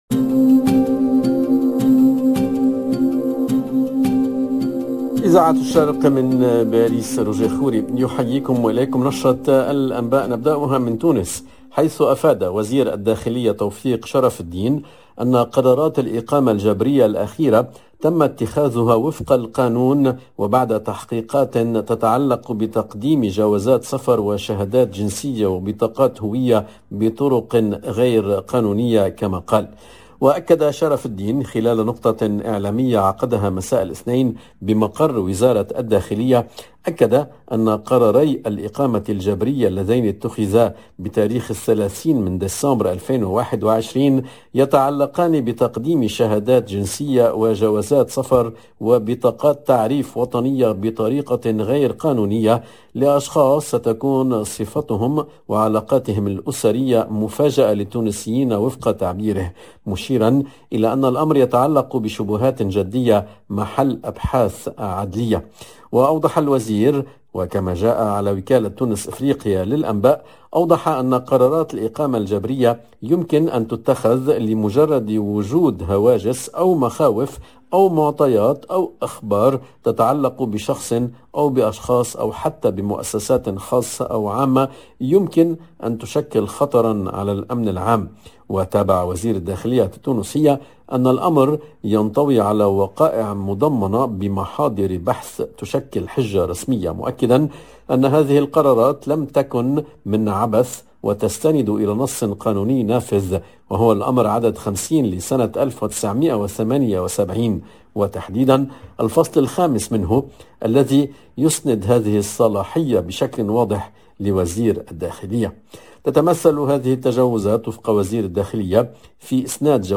LE JOURNAL DE MIDI 30 EN LANGUE ARABE DU 4/01/22